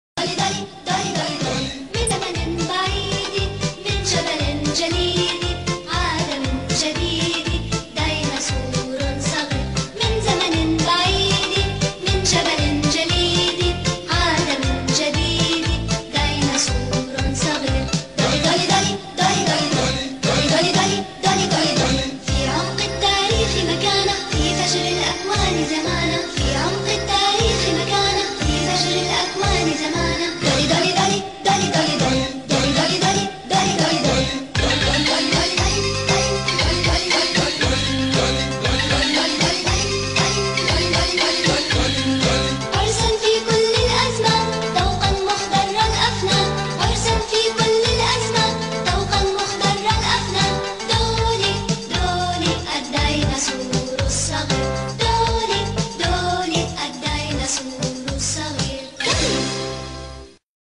دولي الديناصور الصغير - الحلقة 1 مدبلجة